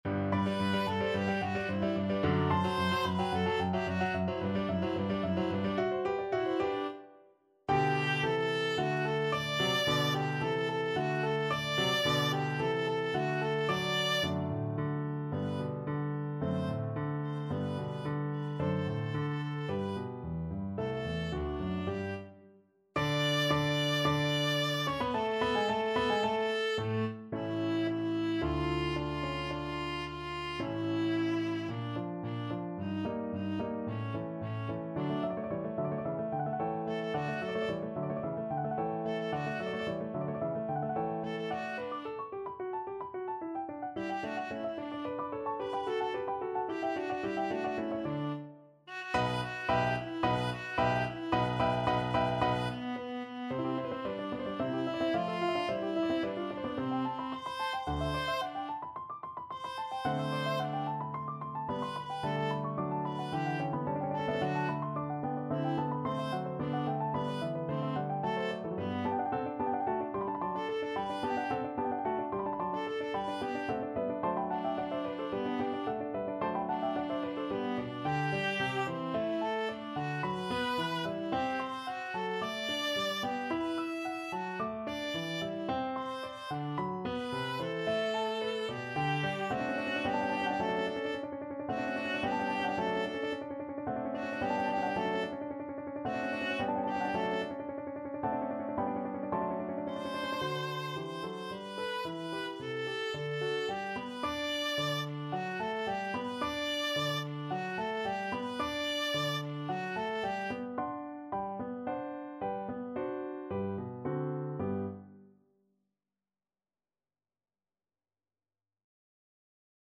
4/4 (View more 4/4 Music)
Allegro assai =220 (View more music marked Allegro)
A4-E6
Classical (View more Classical Viola Music)